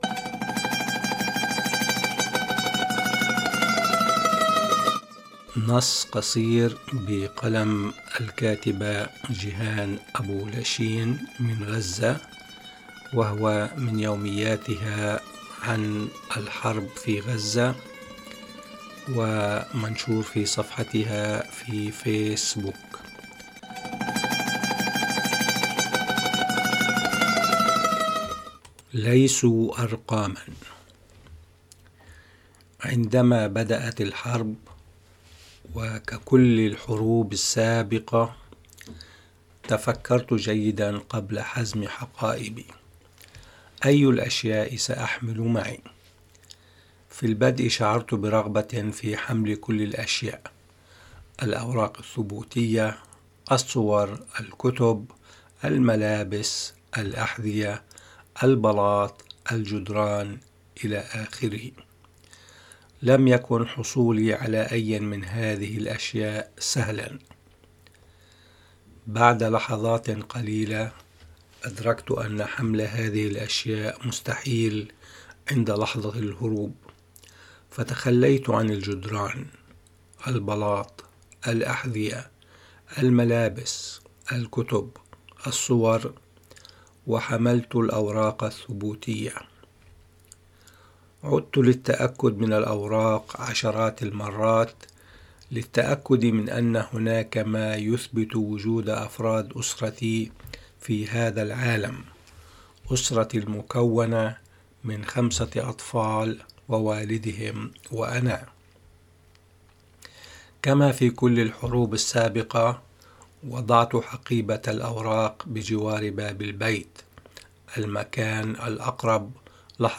الموسيقى المستخدمة في بداية ونهاية البودكاست مقطع من معزوفة للفنان العراقي نصير شما عنوانها "حدث في العامرية".